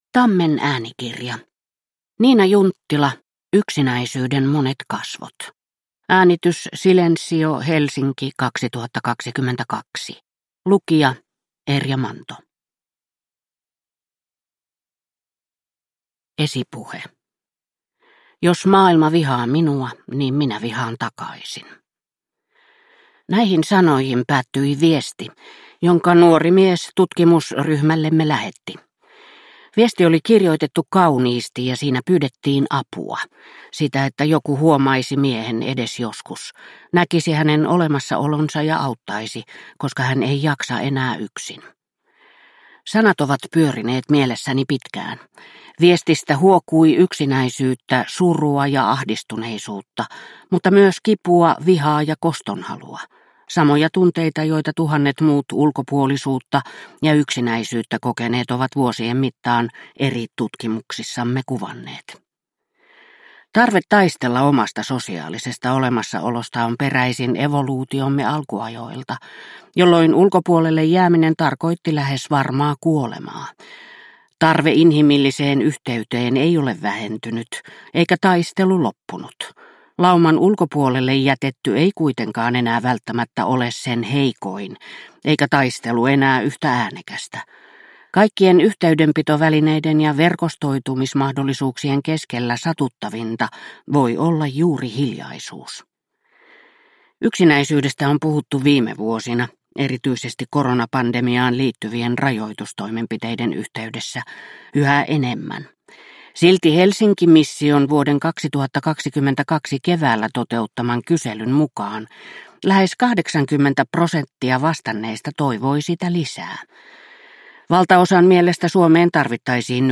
Yksinäisyyden monet kasvot – Ljudbok